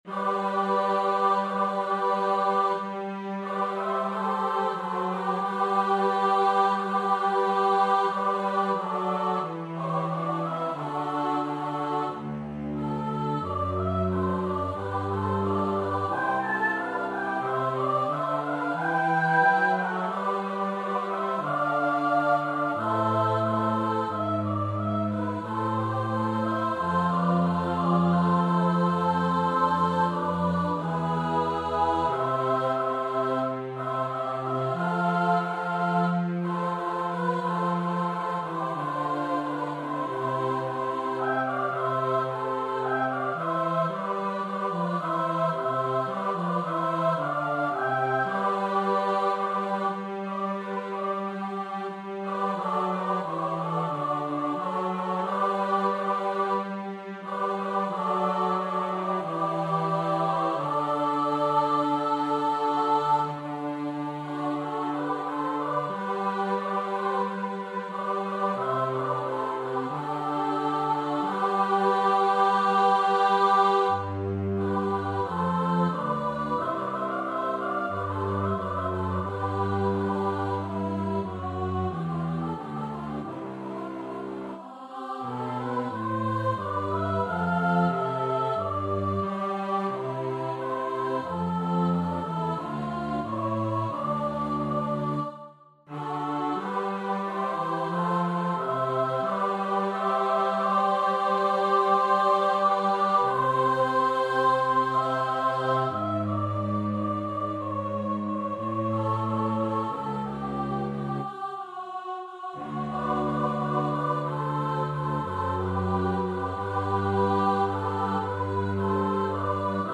Free Sheet music for Choir (SSS)
Soprano 1Soprano 2Soprano 3Continuo
4/4 (View more 4/4 Music)
C major (Sounding Pitch) (View more C major Music for Choir )
Choir  (View more Intermediate Choir Music)
Classical (View more Classical Choir Music)